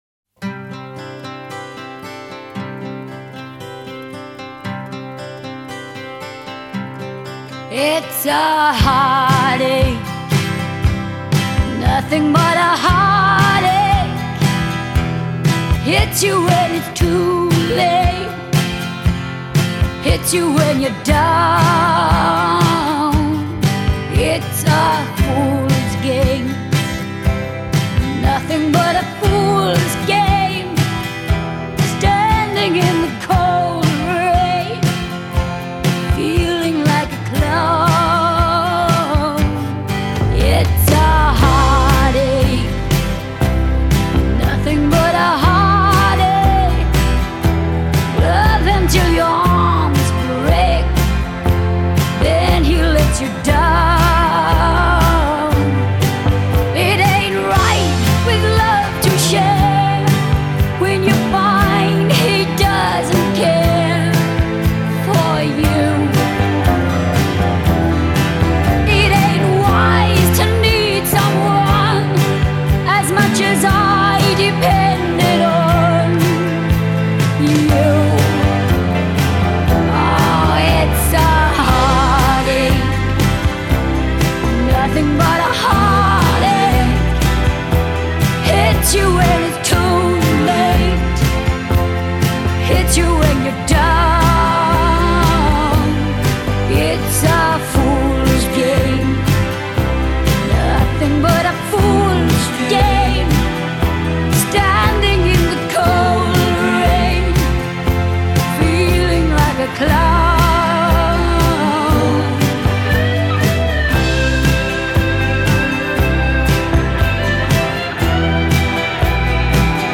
после чего её голос приобрел лёгкую хрипотцу.